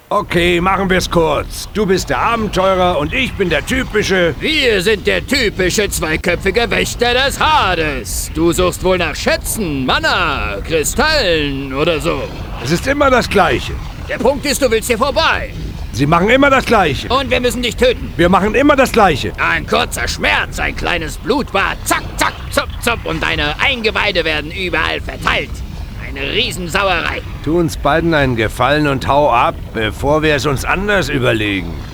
WARNUNG: Die deutsche Fassung ist unverhältnismäßig laut eingebunden worden, entsprechend sind auch die Samples teilweise recht laut.
Die Übersteuerungen sind echt ziemlich krass, da hätte man wirklich bei der Abmischung drauf achten können.